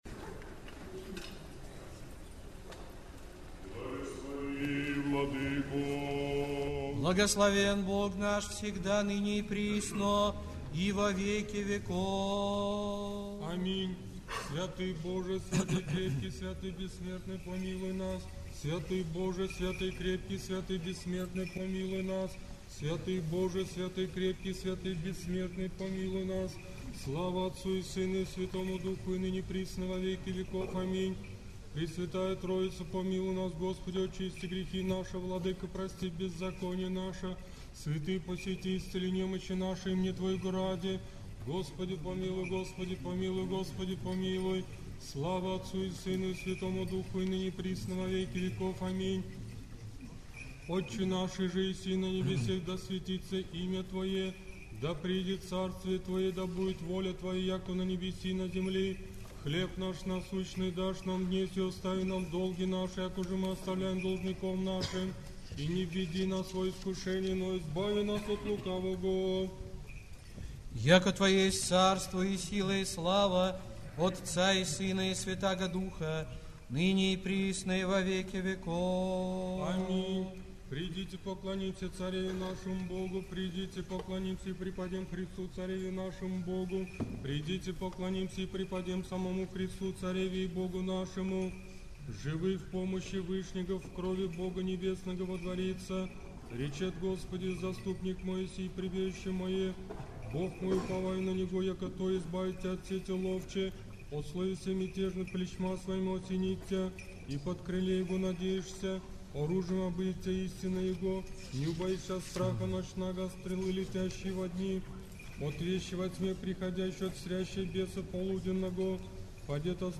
Отпевание